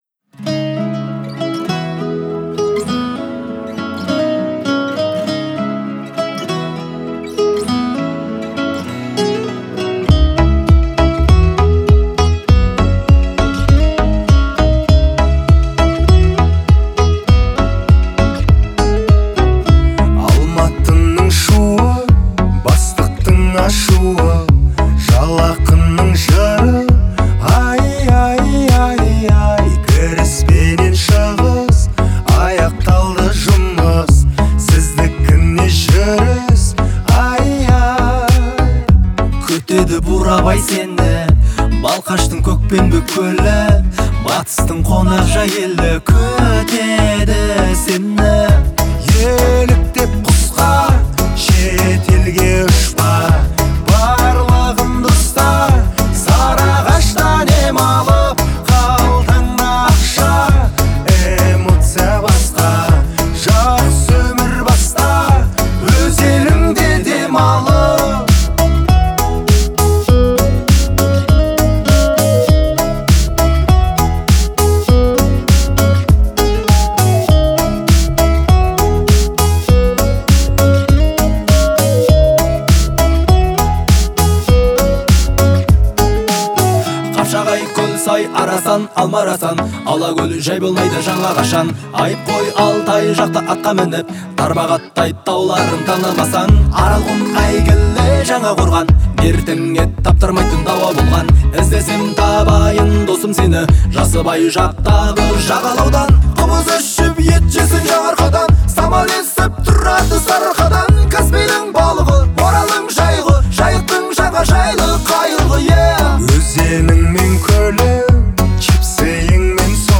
это яркое произведение в жанре поп-фолк